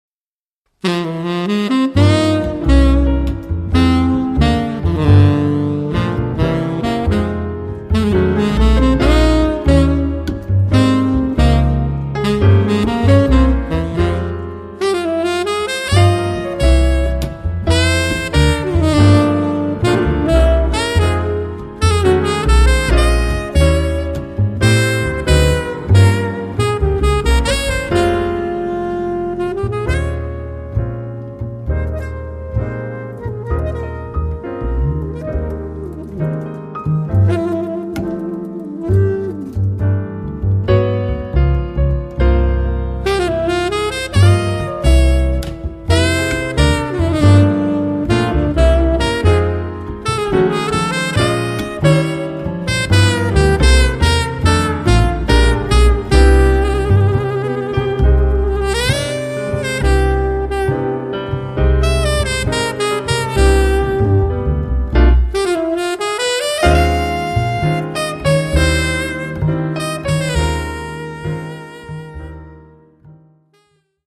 sax tenore & soprano
pianoforte
contrabbasso